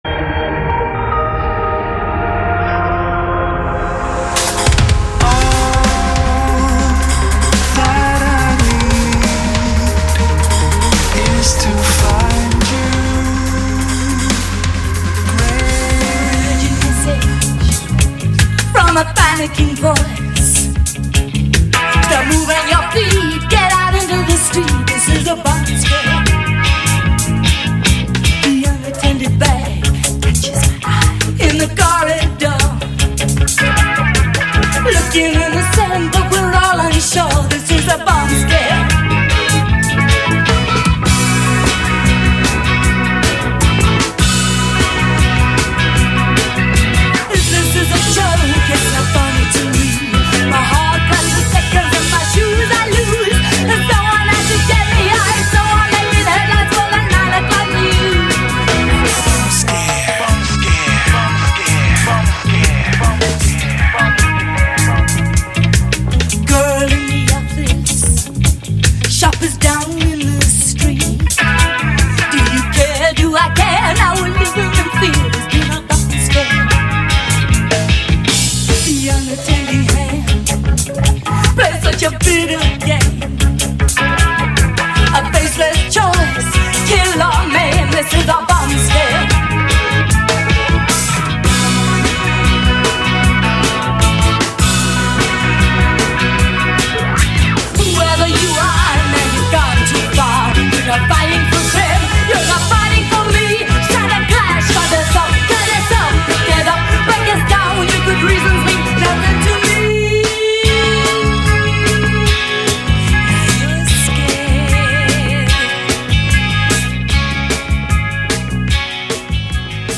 Vos esgourdes seront abreuvées de 50’s Rockabilly